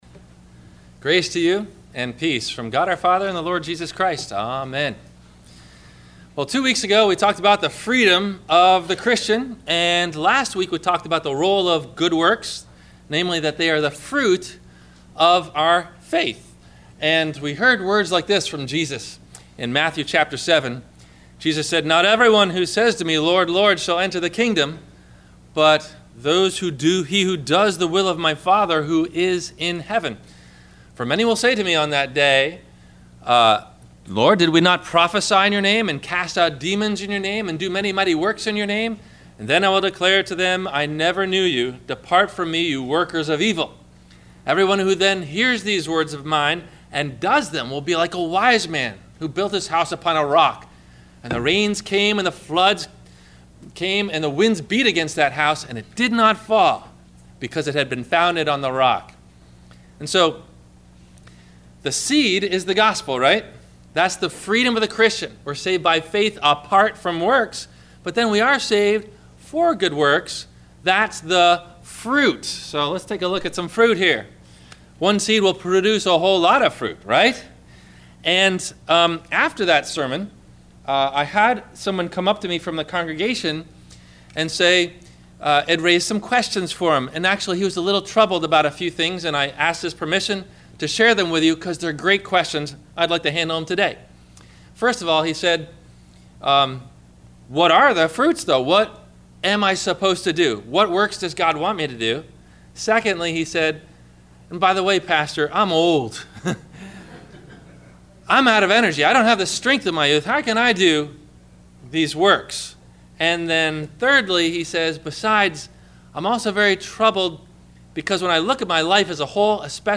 The Fruit of The Christian - Sermon - February 07 2016 - Christ Lutheran Cape Canaveral